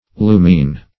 lumine - definition of lumine - synonyms, pronunciation, spelling from Free Dictionary Search Result for " lumine" : The Collaborative International Dictionary of English v.0.48: Lumine \Lu"mine\, v. i. To illumine.